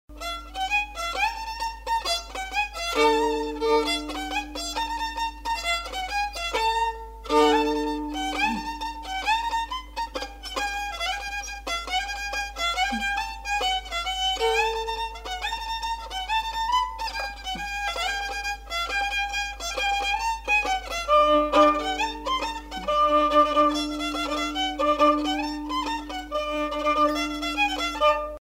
Aire culturelle : Lomagne
Lieu : Garganvillar
Genre : morceau instrumental
Descripteurs : rondeau
Instrument de musique : violon